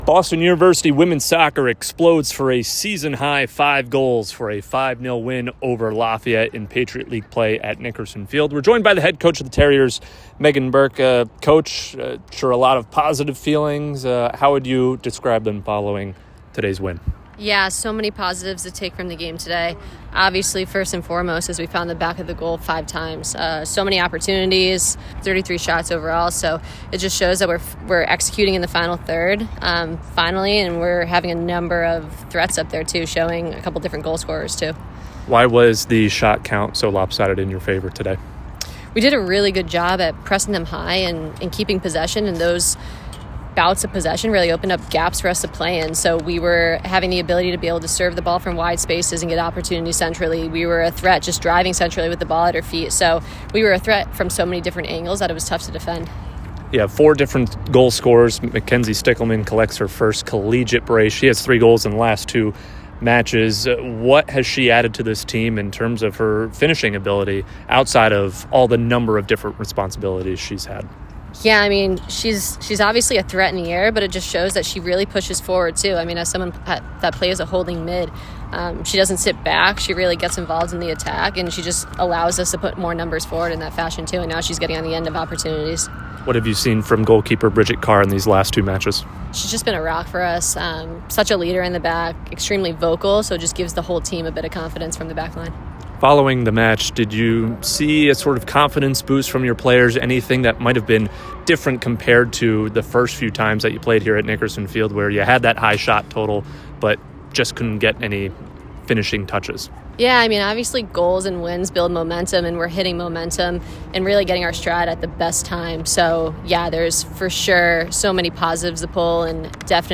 Lafayette Postgame Interview